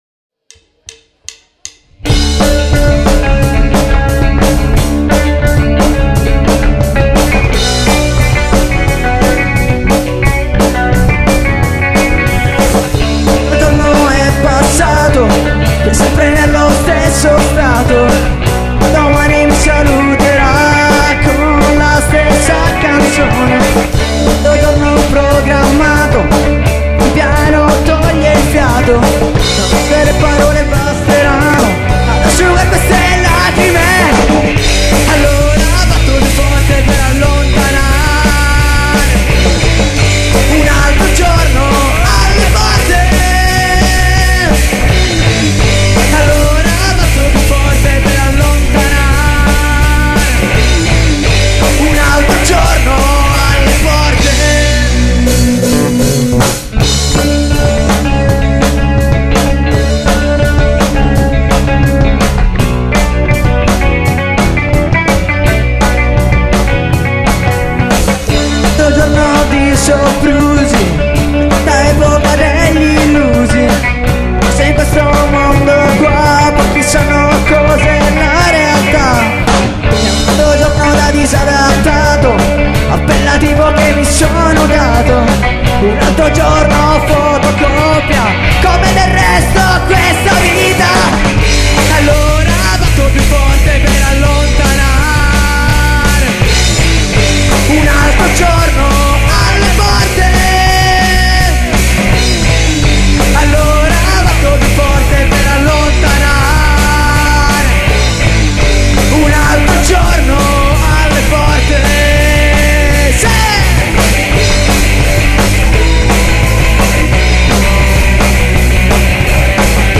Genere: Rock Contaminato